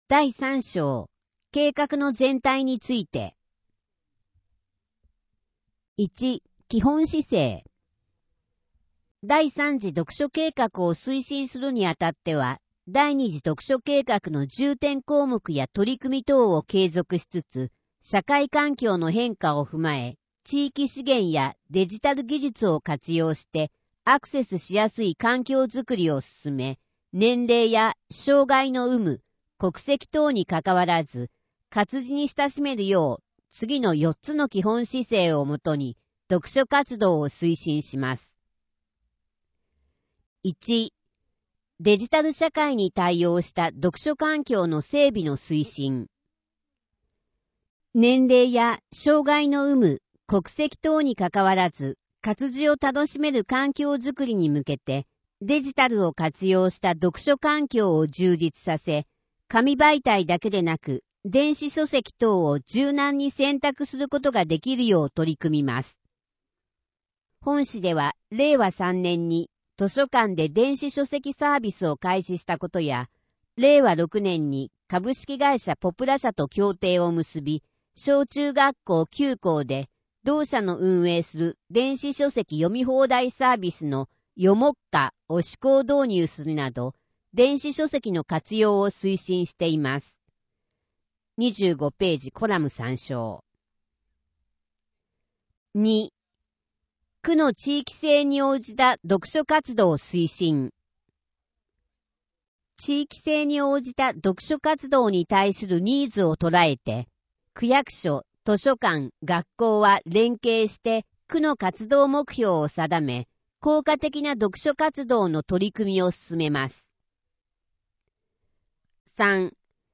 語音版